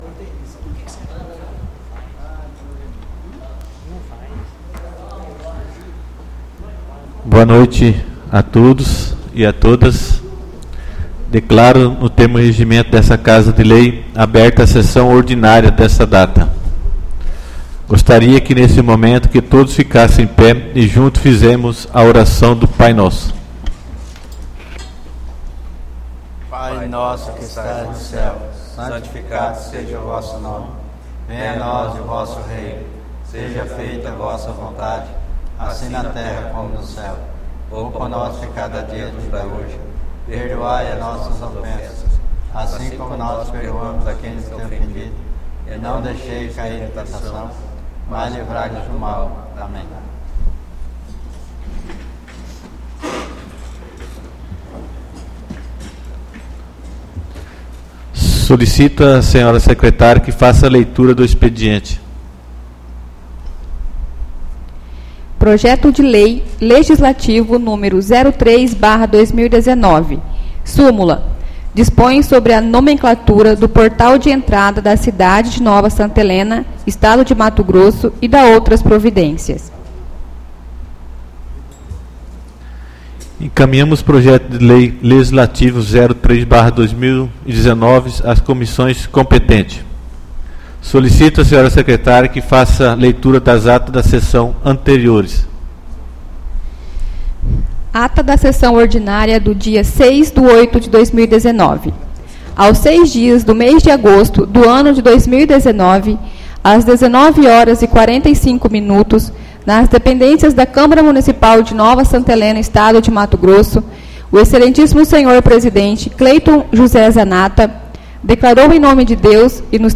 Áudio da Sessão Ordinária 13/08/2019